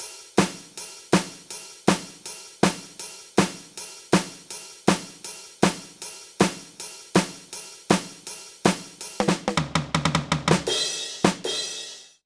British ROCK Loop 158BPM (NO KICK).wav